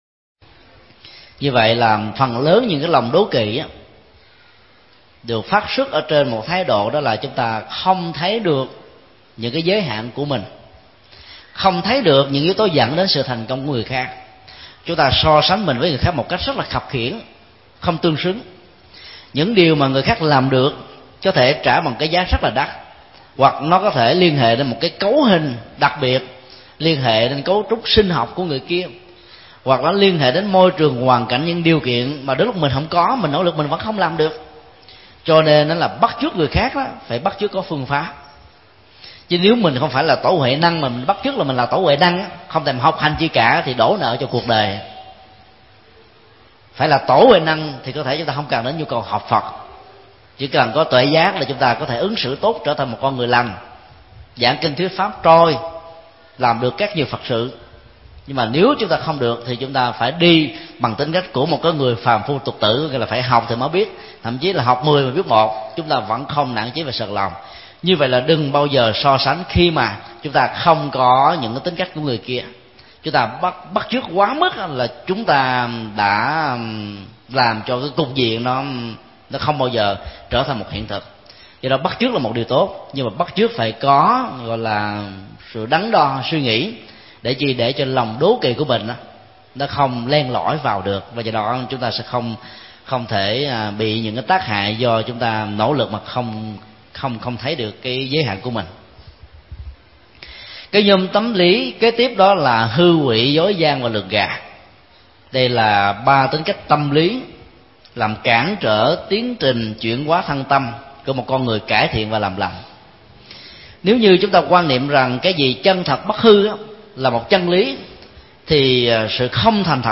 Mp3 Pháp Thoại Kinh Trung Bộ 15 (Kinh Tư Lượng) – Chuyển hóa nhân cách 2
Giảng tại chùa Xá Lợi